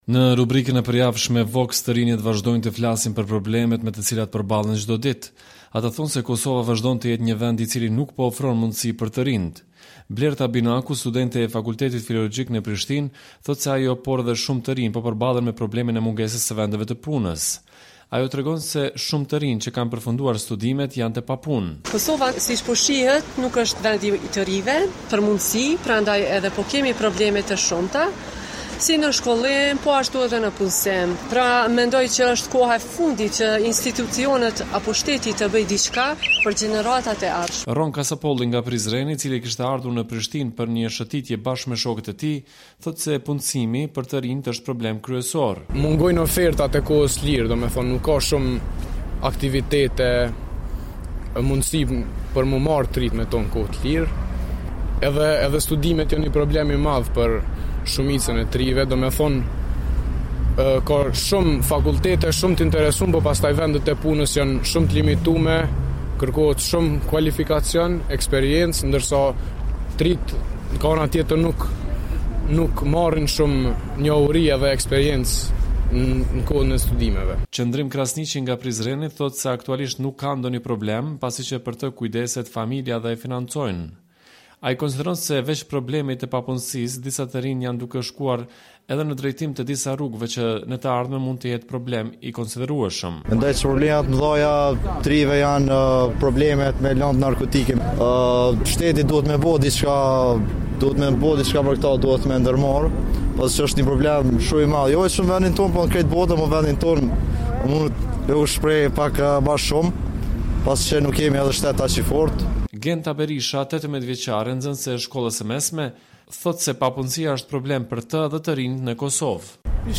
Në rubrikën e përjavshme “VOX” të Radios Evropa e Lirë, të rinjtë vazhdojnë të flasin për problemet, me të cilat përballen çdo ditë.